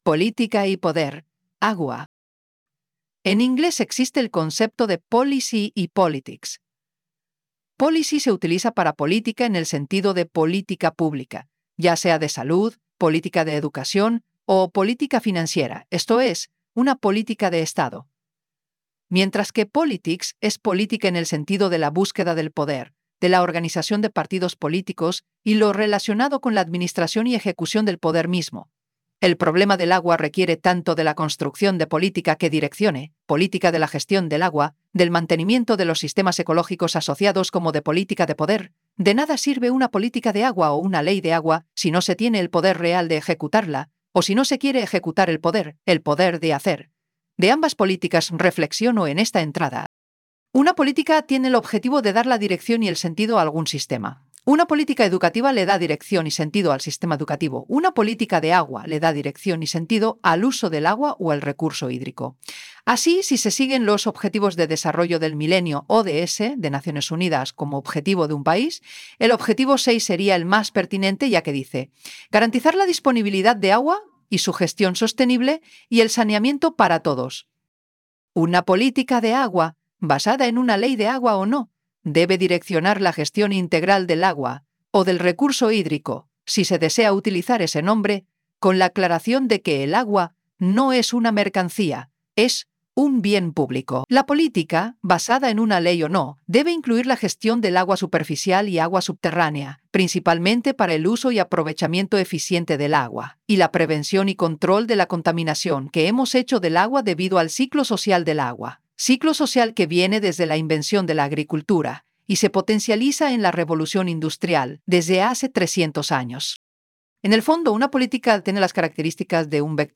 PlayAI_Poli_tica_y_poder_Agua.wav